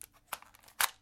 手枪 重新装弹，扣动扳机，射击的声音效果
描述：为我正在制作的东西重新加载和竖起BB枪。
标签： 公鸡 手枪 弹匣 重新加载 武器 手枪 夹子 BB枪 射击
声道立体声